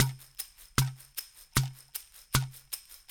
AFP SHAKER-L.wav